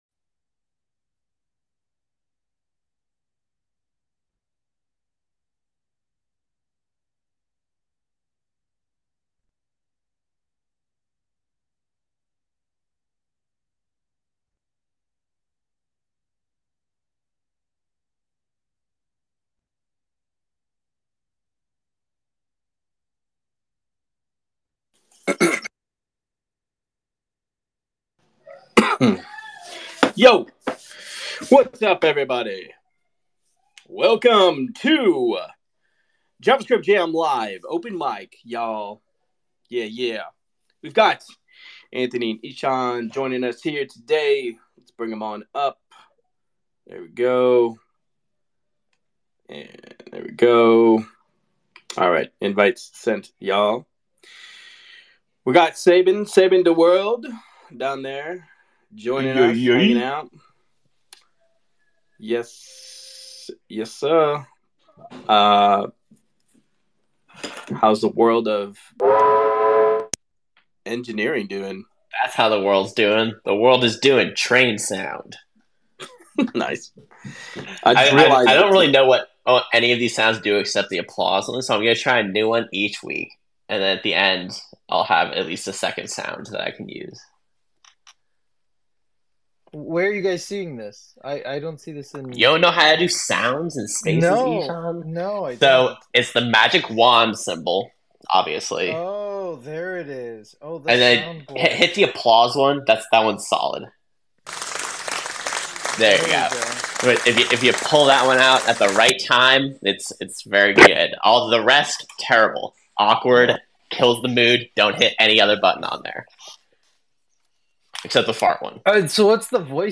An open discussion about Chrome’s new features, WordPress’s 100-year plan, future of AI, feature flags, and Redwood’s approach to React Server Components